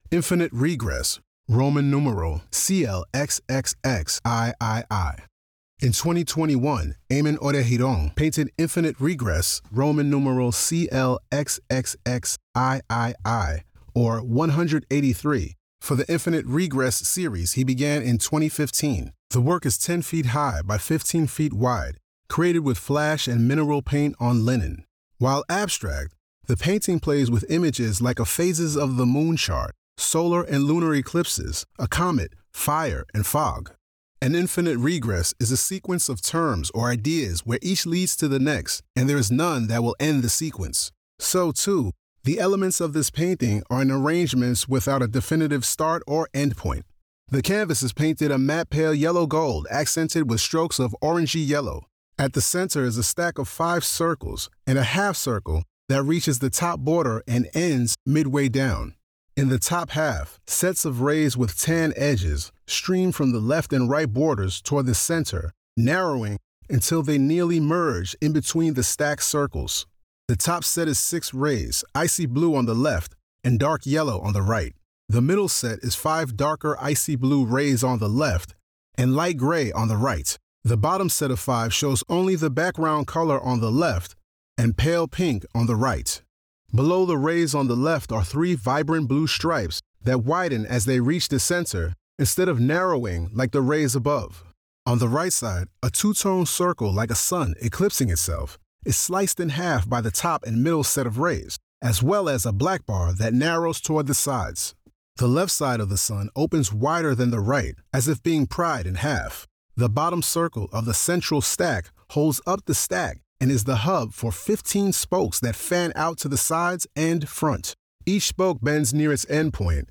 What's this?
Audio Description (03:43)